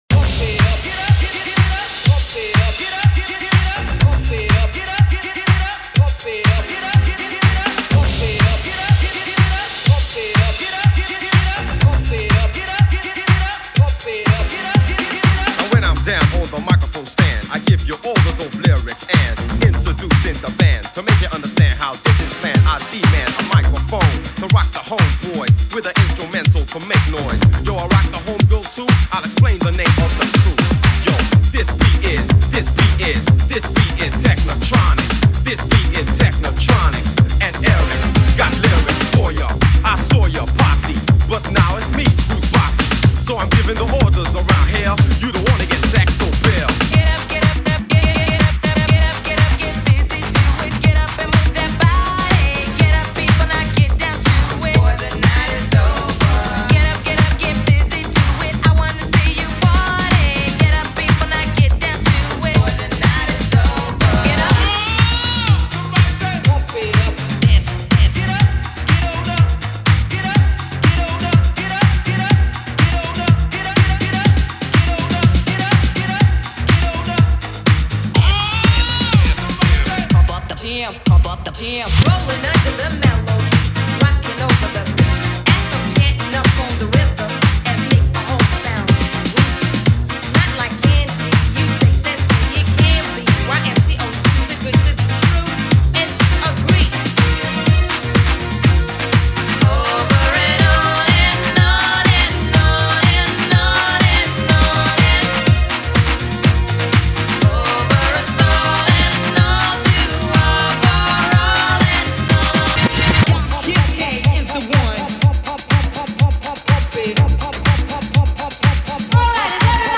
High-Energy Miami Club Remix